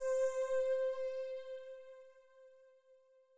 INT String C4.wav